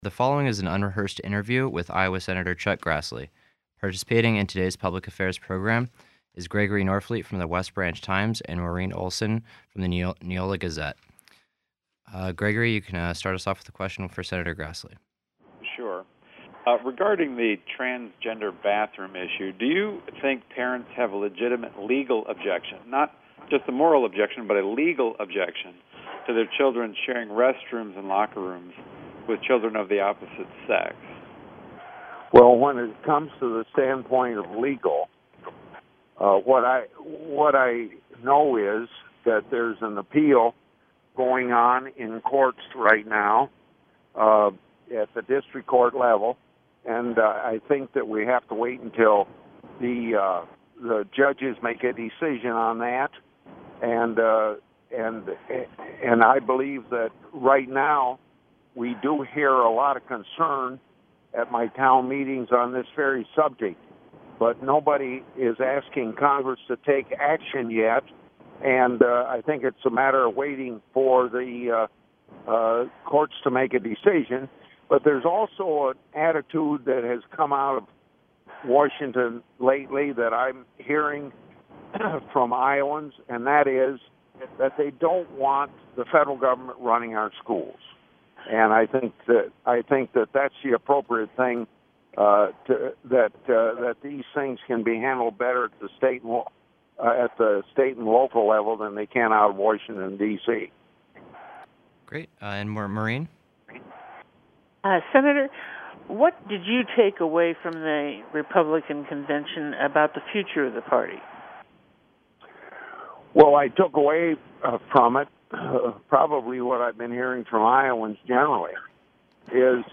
GRASSLEY_INTV_72816.mp3